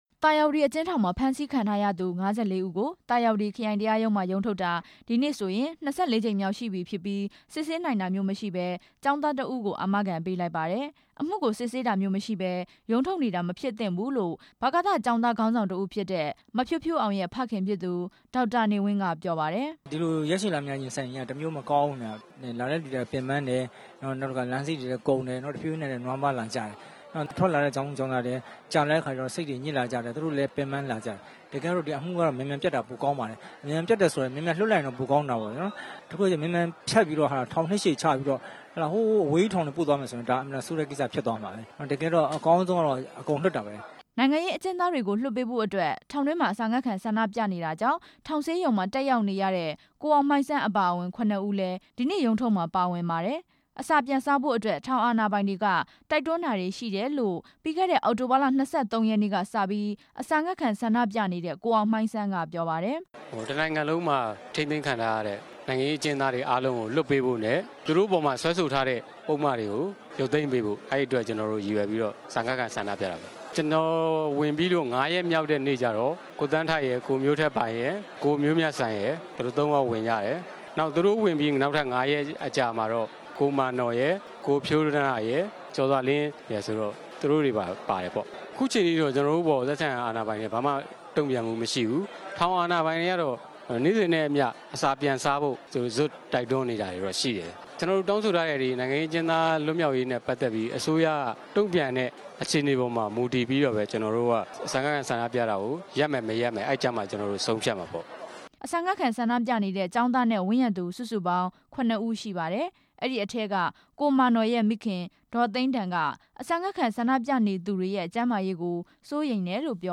သာယာဝတီကနေ သတင်းပေးပို့ထားပြီး